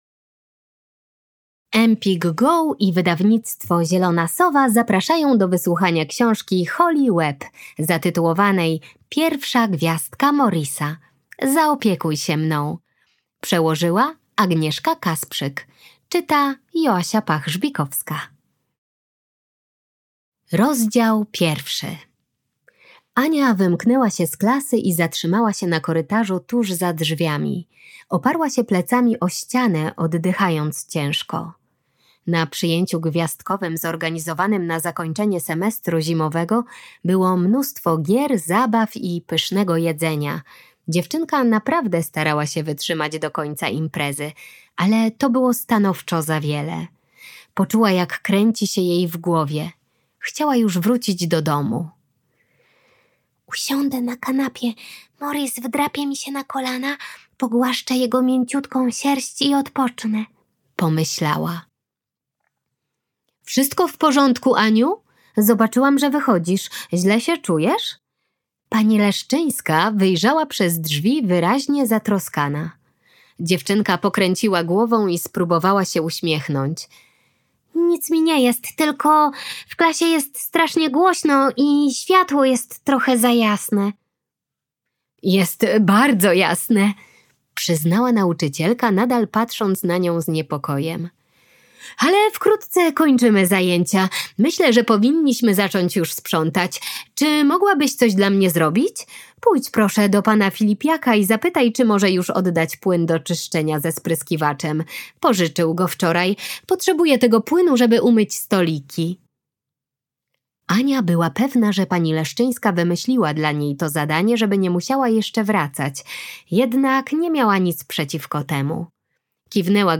Zaopiekuj się mną. Pierwsza Gwiazdka Morisa - Holly Webb - audiobook